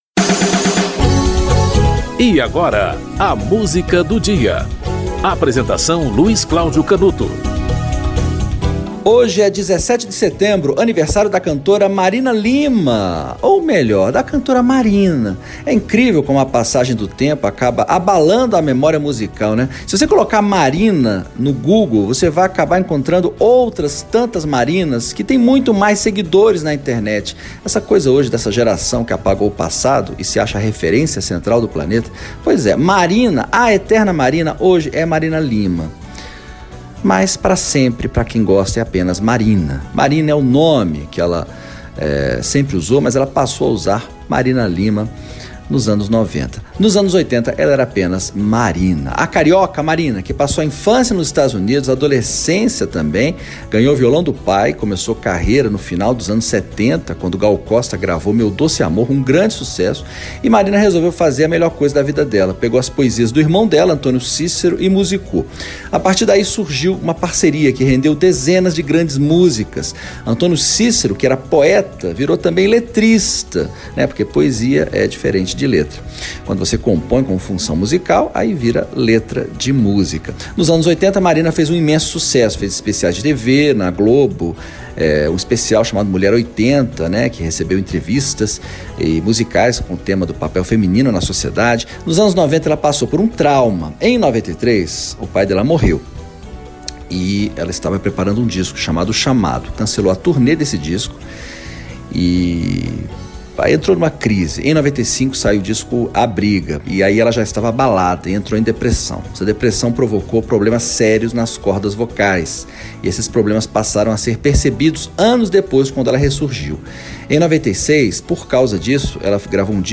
Programas da Rádio Câmara